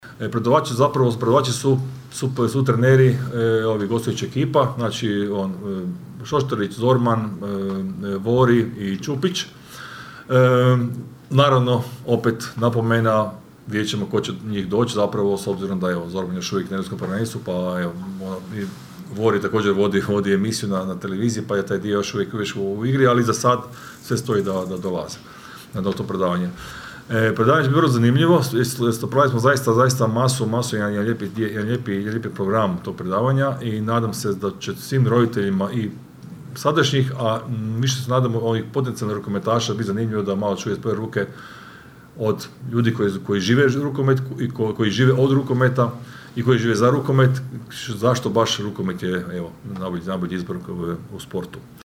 na konferenciji za novinare